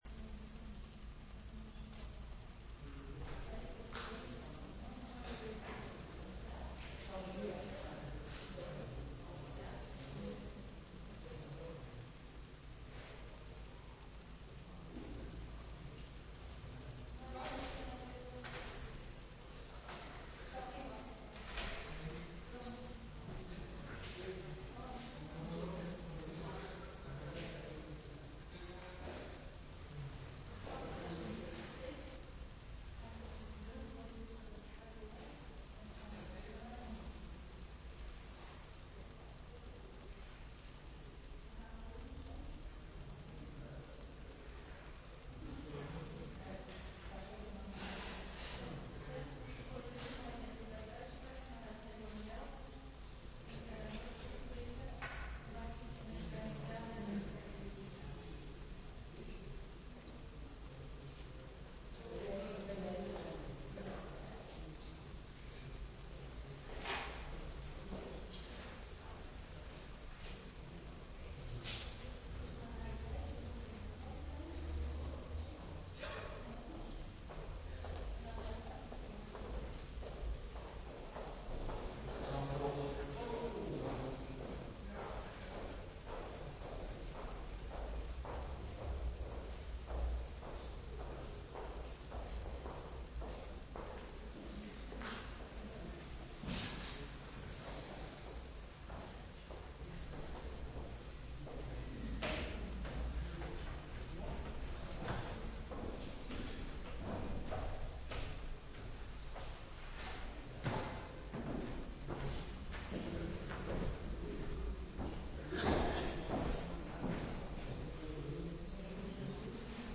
Preek over Genesis 22:1-14 - Pauluskerk Gouda